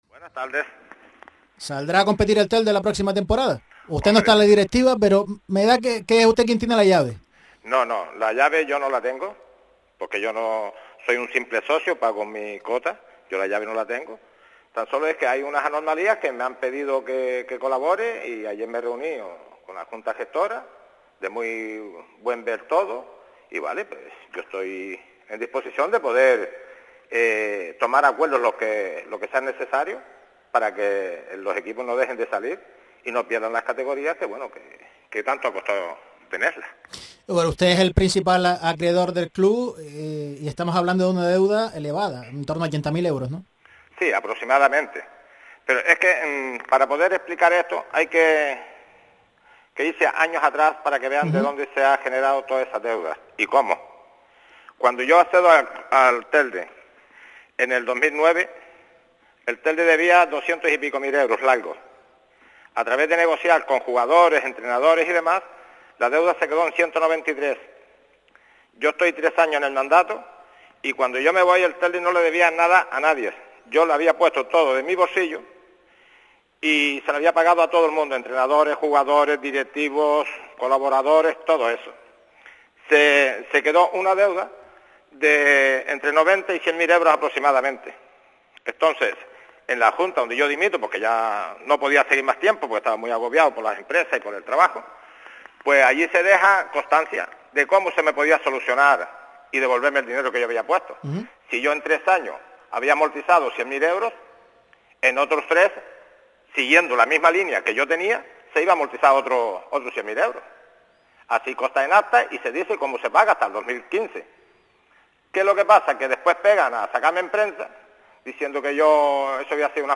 Todos estos aspectos y algunos más se abordaron en la emisora autonómica de Canarias.
AUDIO / CORTESÍA CANARIAS RADIO.